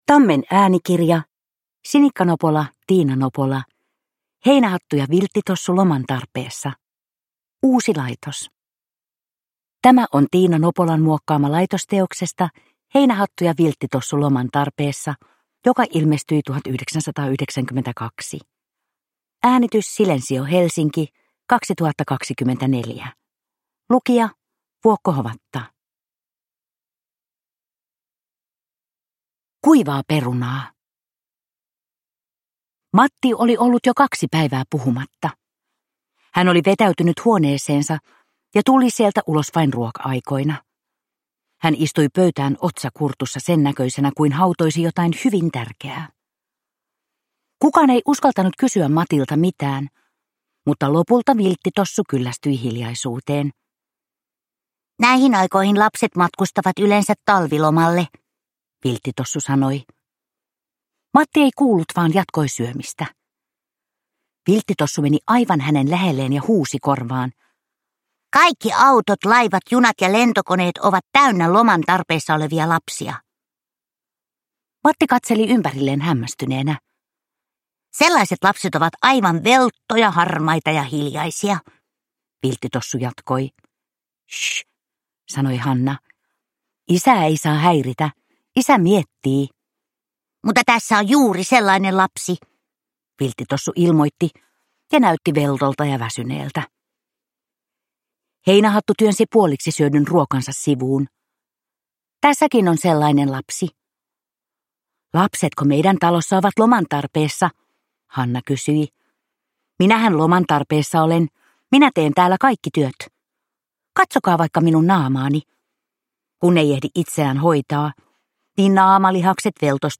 Heinähattu ja Vilttitossu loman tarpeessa, uusi laitos – Ljudbok